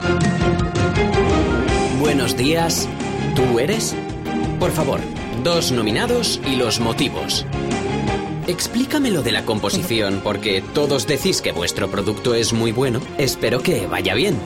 Speaker madrelingua spagnolo dalla voce calda e versatile.
Sprechprobe: Werbung (Muttersprache):
Native Spanish voice-artist with a warm and versatile voice.